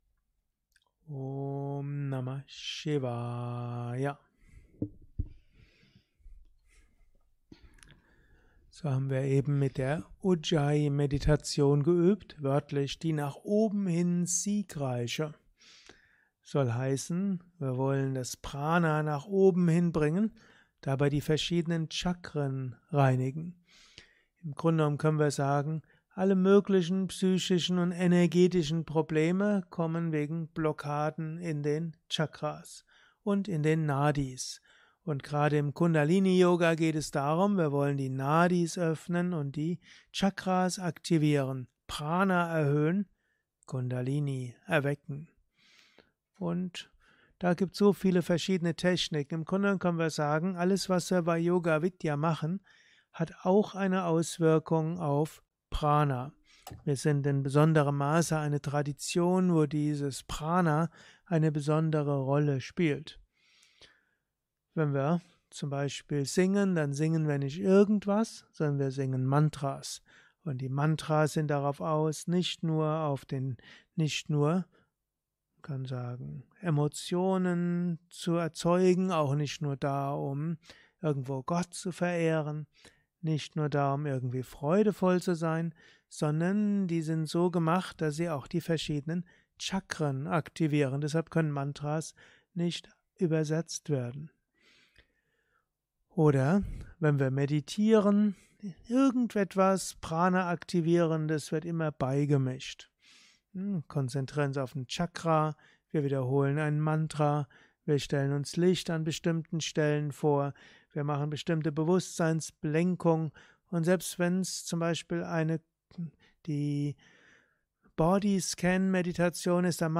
kurzer Vortrag als Inspiration für den heutigen Tag von und mit
Satsangs gehalten nach einer Meditation im Yoga Vidya Ashram Bad